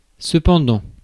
Ääntäminen
UK : IPA : /bʌt/ stressed: IPA : /bʌt/ US : IPA : /bʌt/